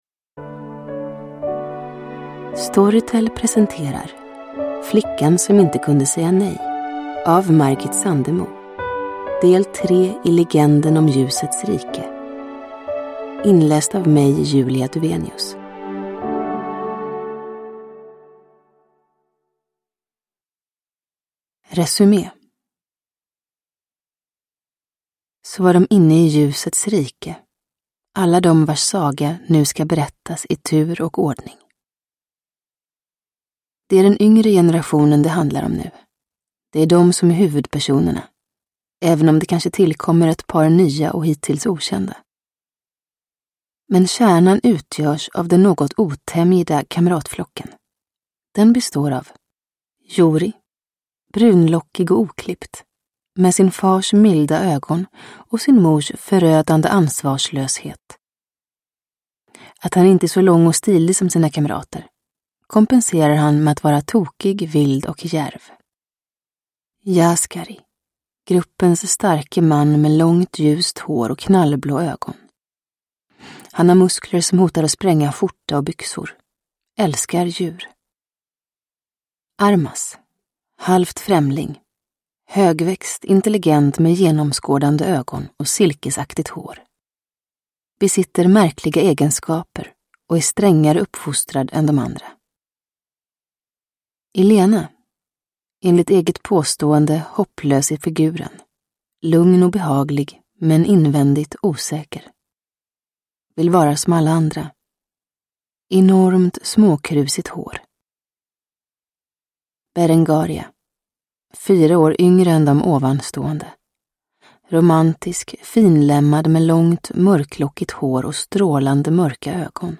Flickan som inte kunde säga nej – Ljudbok – Laddas ner